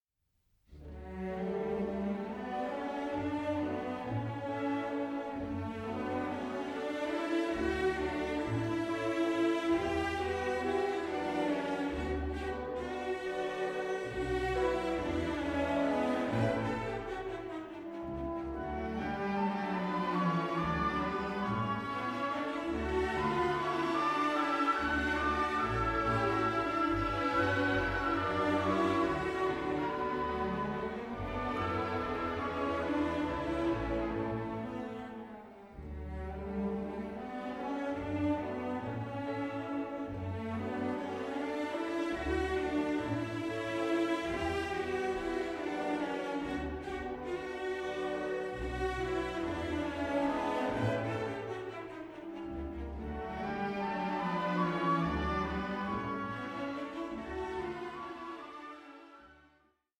(DSD DSF) Stereo & Surround  20,99 Select